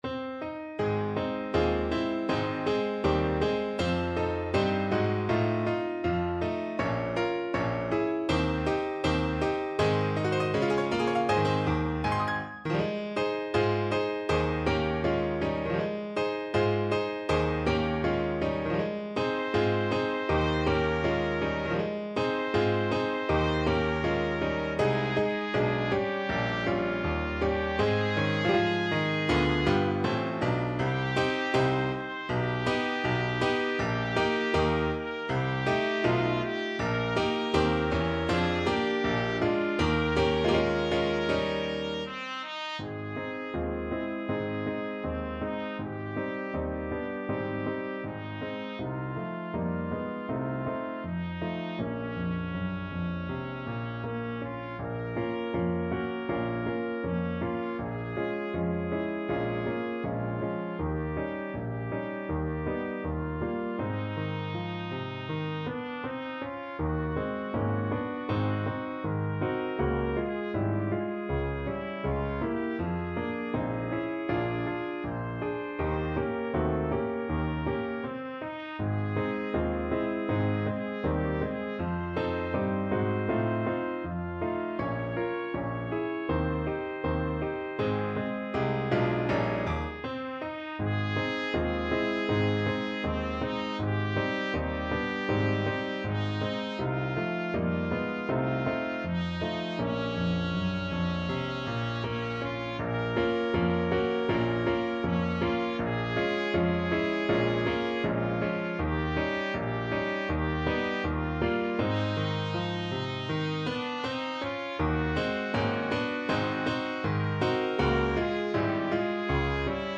2/2 (View more 2/2 Music)
Moderato =80
Pop (View more Pop Trumpet Music)